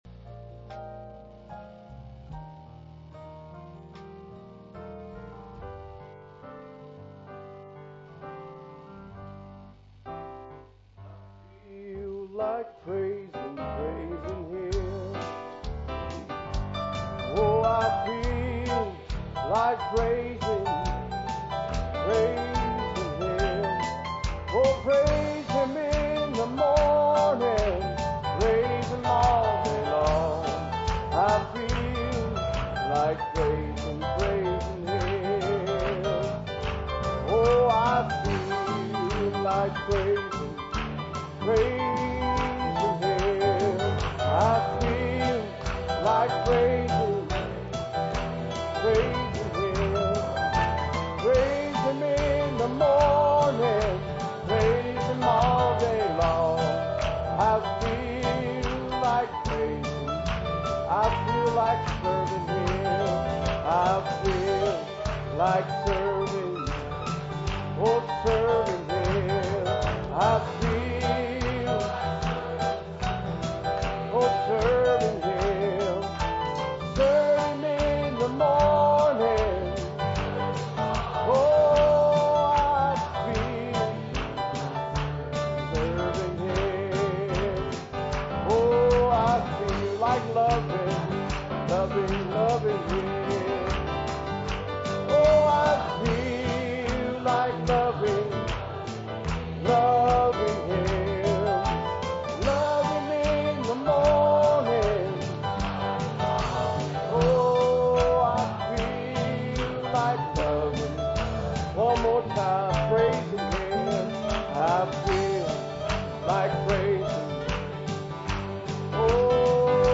For this or any other sermon on DVD, please contact the library using the contact form on the website.